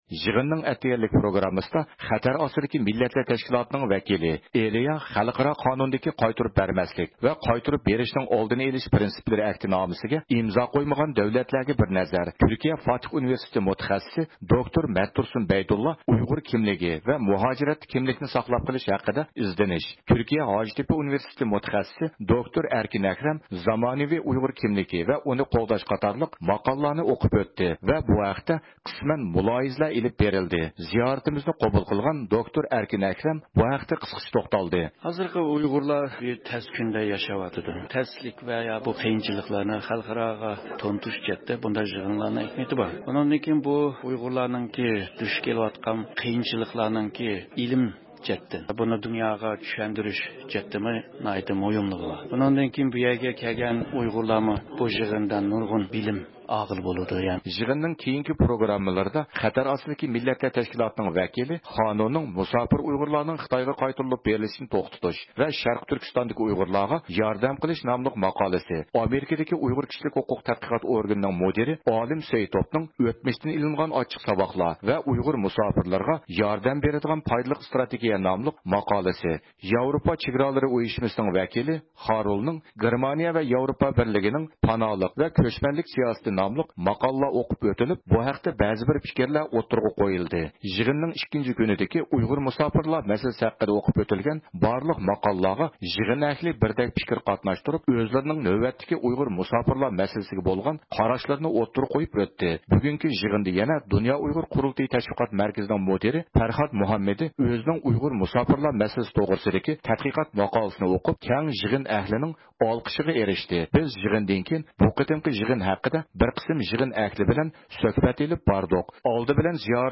بىز يىغىندىن كېيىن بۇ قېتىمقى يىغىن ھەققىدە بەزى بىر قاراشلارغا ئىگە بولۇش ئۈچۈن بىر قىسىم يىغىن ئەھلى بىلەن سۆھبەت ئېلىپ باردۇق.
دۇنيا ئۇيغۇر قۇرۇلتىيى ئىجرائىيە كومىتېتىنىڭ مۇدىرى دولقۇن ئەيسا بۇ قېتىمقى يىغىننىڭ ئەھمىيىتى ھەققىدە توختالدى.